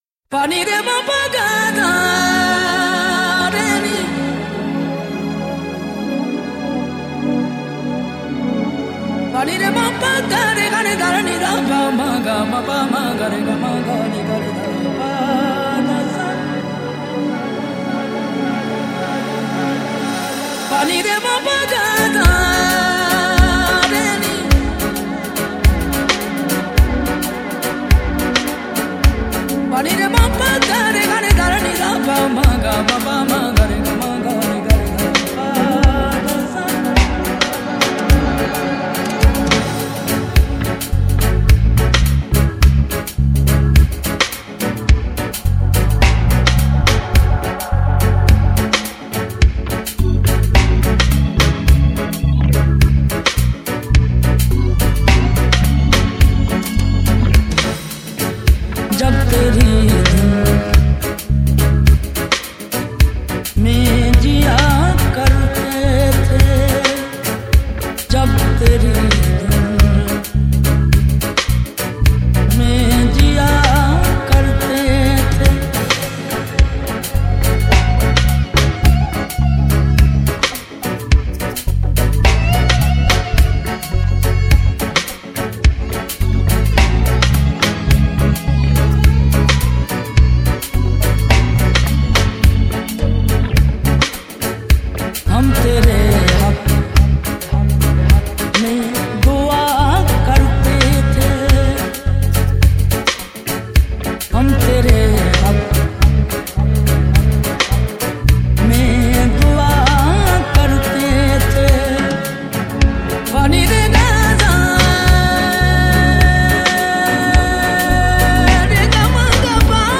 Sufi Collection